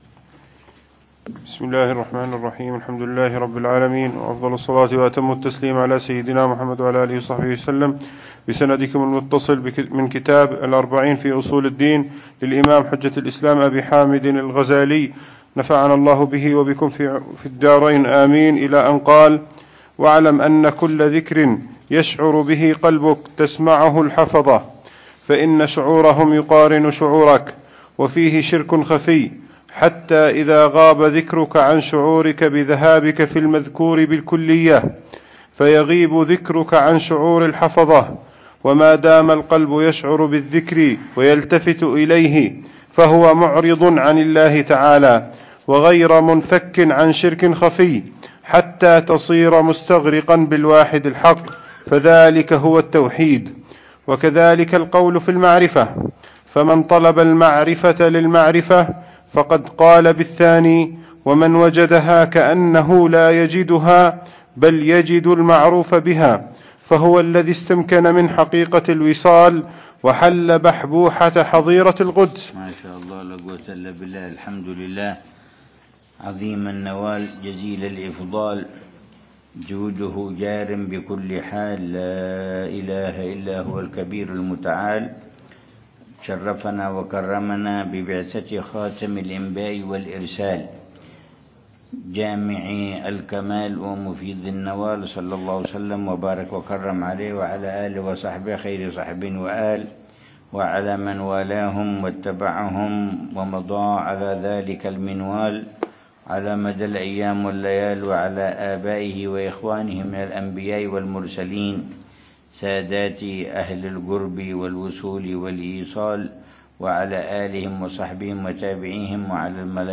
الدرس الحادي عشر للعلامة الحبيب عمر بن محمد بن حفيظ في شرح كتاب: الأربعين في أصول الدين، للإمام الغزالي .